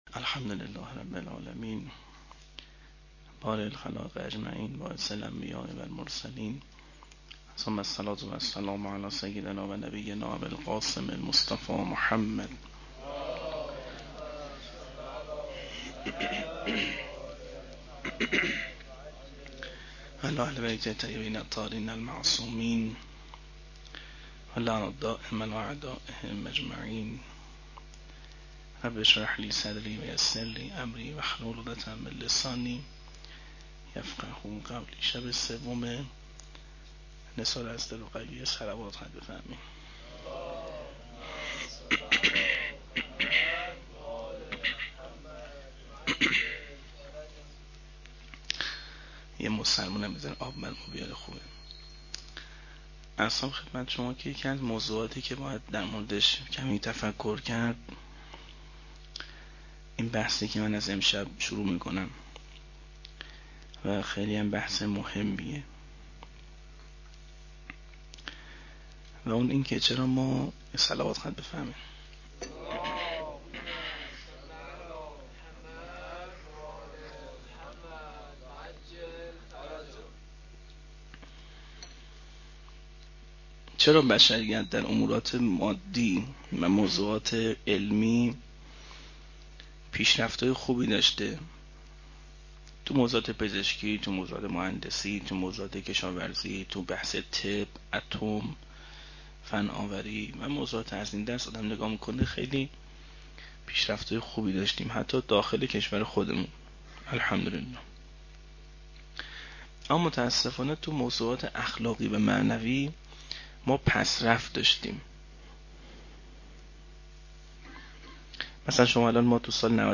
01-shab3-sokhanrani.mp3